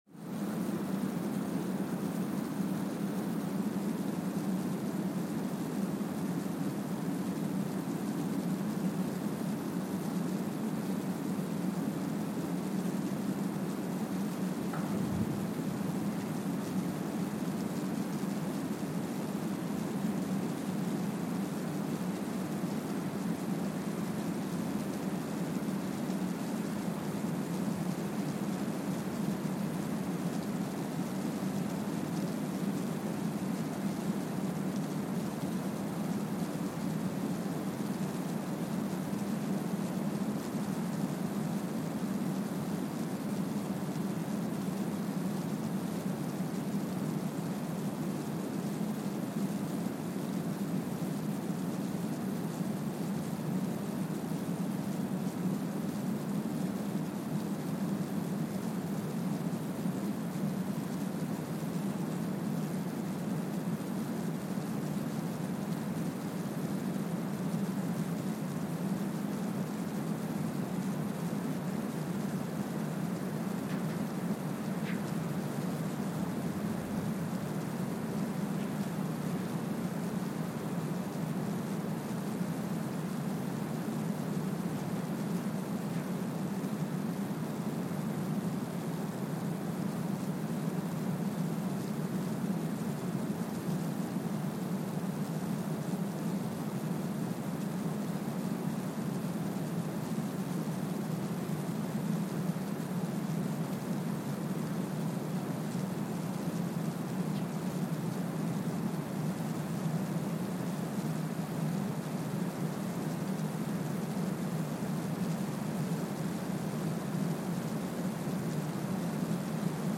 Kwajalein Atoll, Marshall Islands (seismic) archived on November 22, 2023
Sensor : Streckeisen STS-5A Seismometer
Speedup : ×1,000 (transposed up about 10 octaves)
Loop duration (audio) : 05:45 (stereo)
Gain correction : 25dB